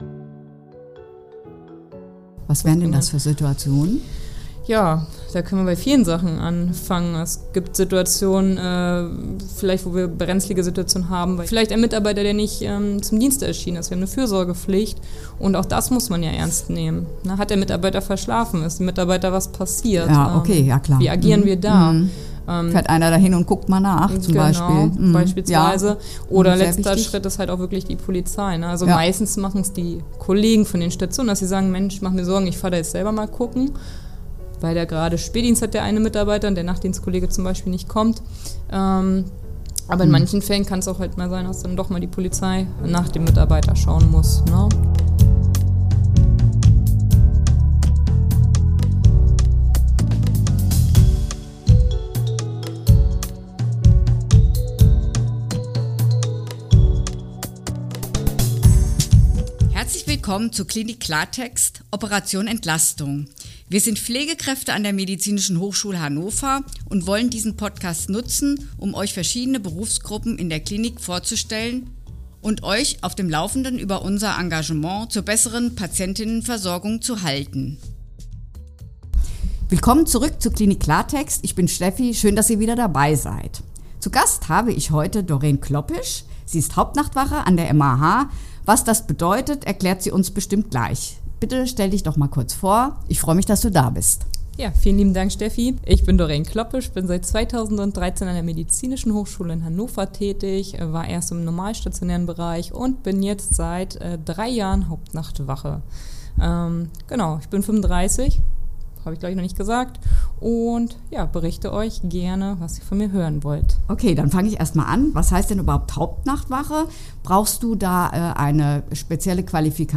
Hauptnachtwache – Ein Interview